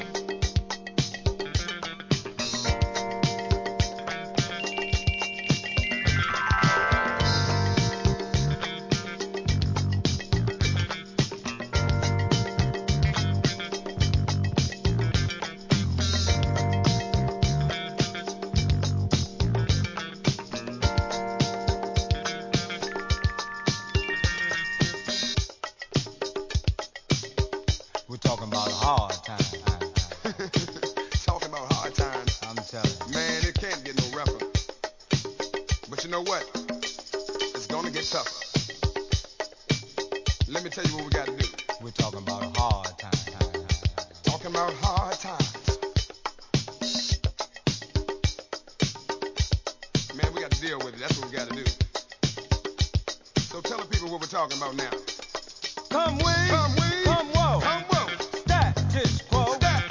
メロ〜なGO-GO BEATにRAPを乗せたOLD SCHOOL DISCO RAP!!!